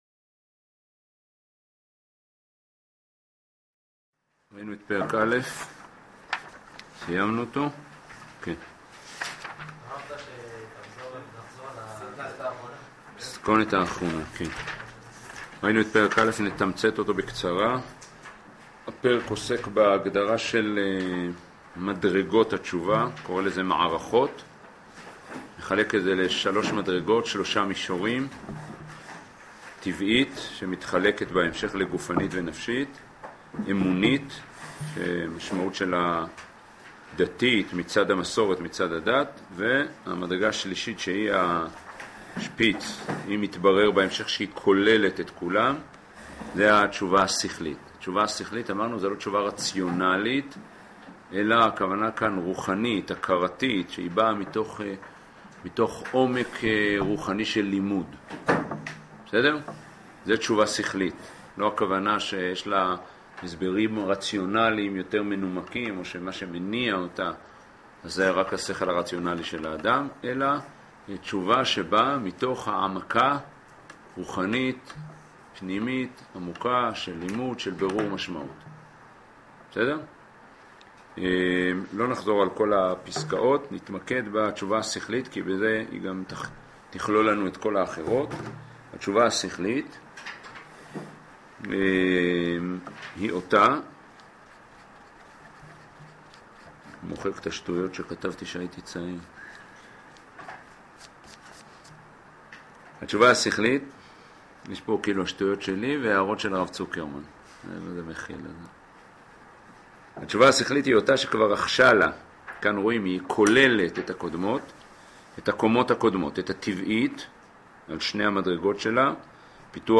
שיעור זה הוא חלק מ: אורות התשובה [תשע"ה]